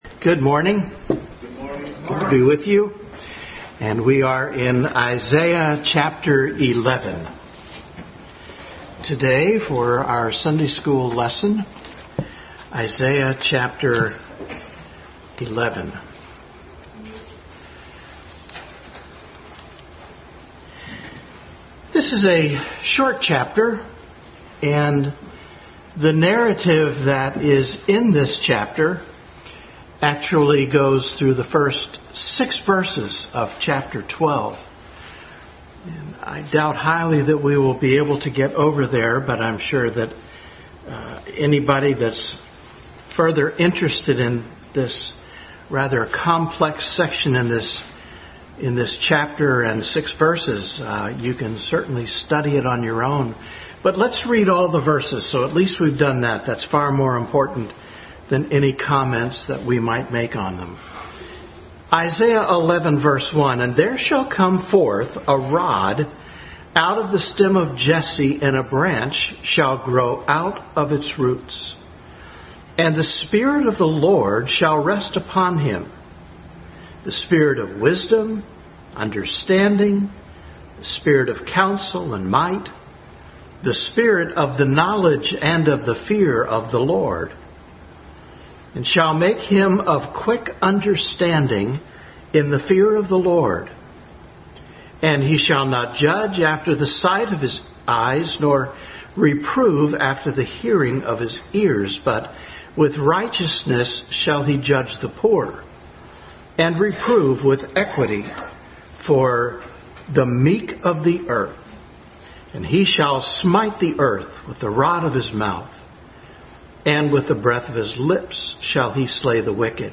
Adult Sunday School Class continued study of Christ in Isaiah.
Isaiah 11:1-12:6 Service Type: Sunday School Adult Sunday School Class continued study of Christ in Isaiah.